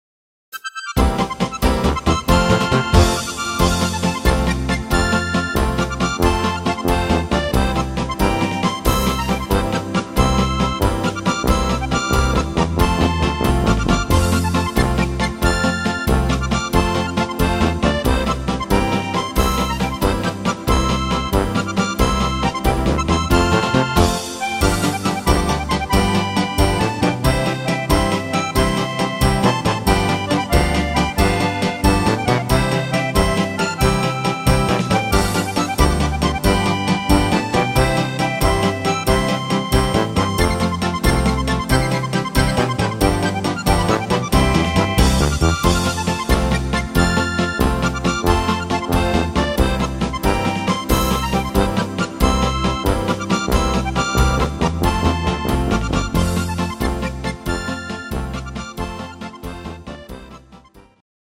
instr. steirische Harmonka